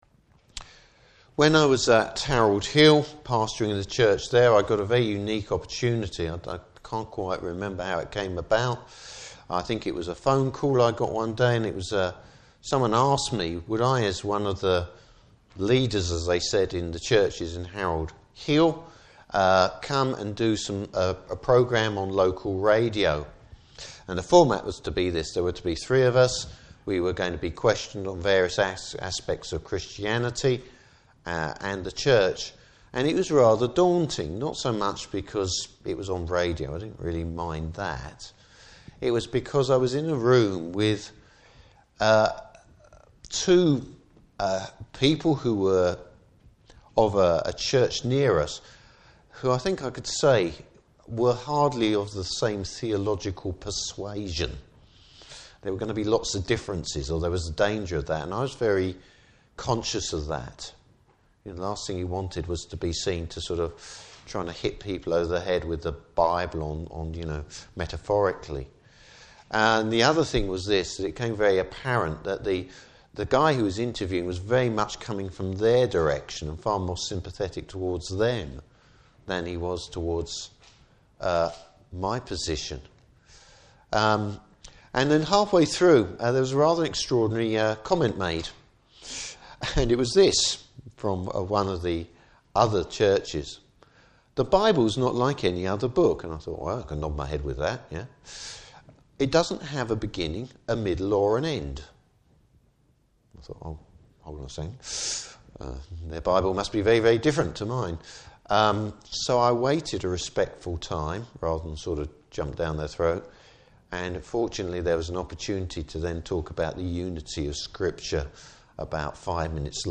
Service Type: Morning Service The Origin of Mankind’s fallen state.